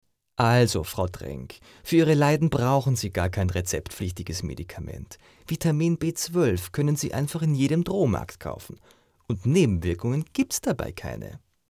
Sprecher für Werbung, Off, Industrie, eLearning,
Sprechprobe: Sonstiges (Muttersprache):